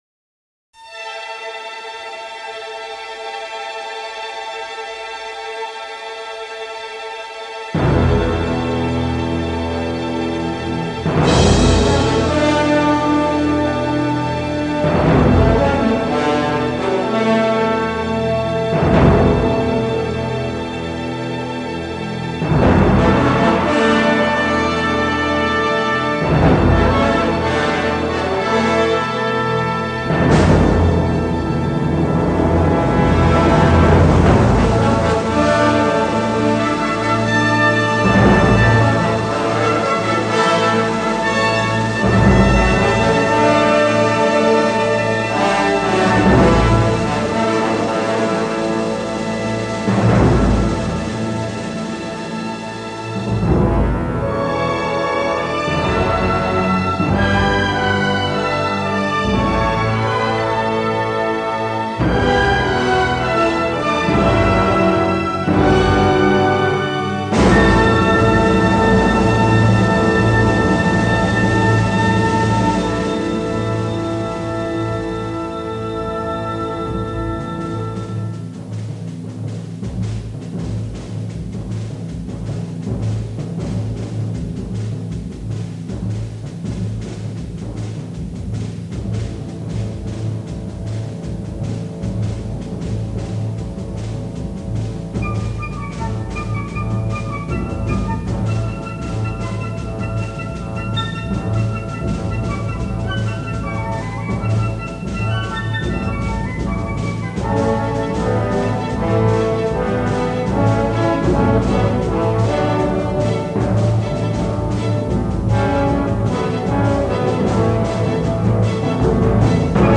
Wind Ensemble